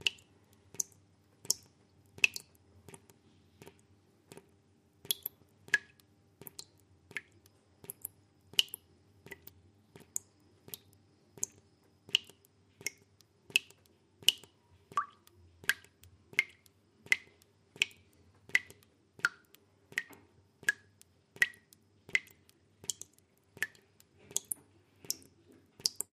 WaterDrops TE36429
Water Drops